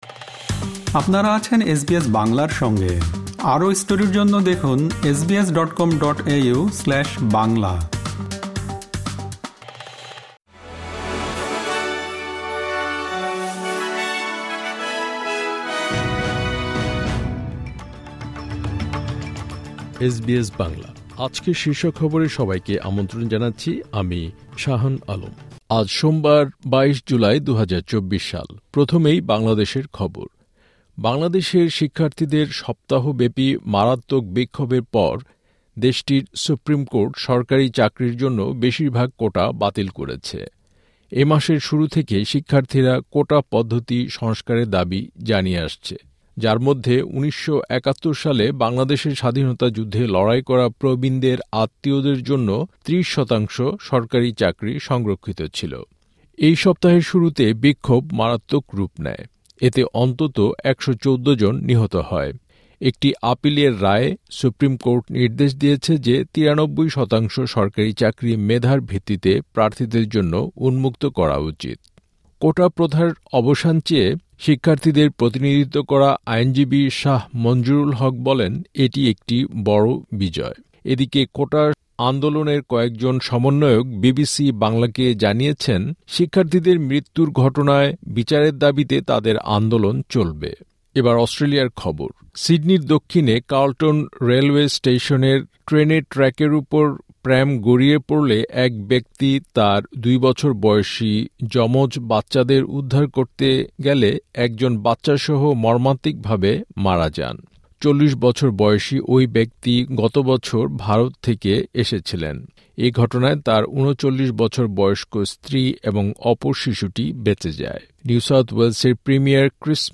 এসবিএস বাংলা শীর্ষ খবর: ২২ জুলাই, ২০২৪